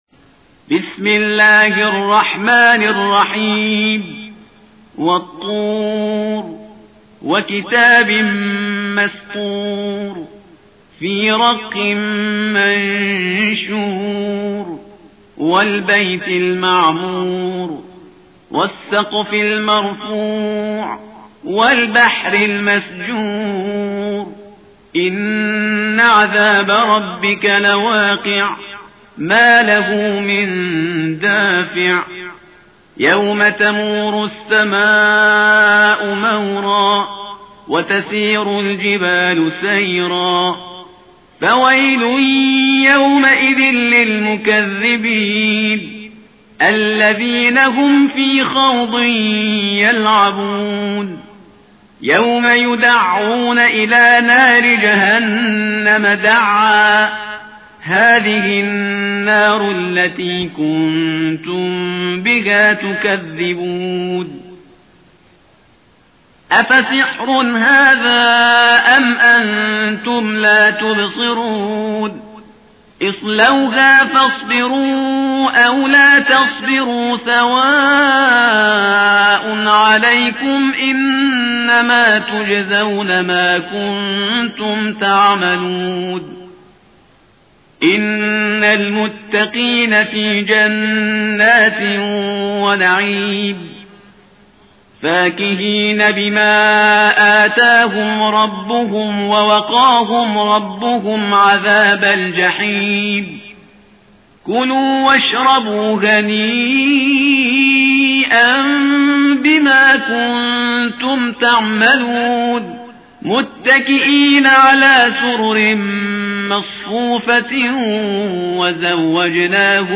فایل صوتی تلاوت سوره طور با صدای شهریار پرهیزگار را در اینجا دریافت کنید.